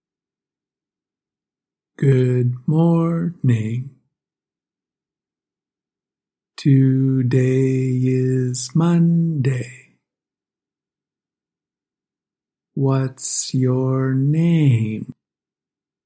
Tell me the tones as I say these sentences: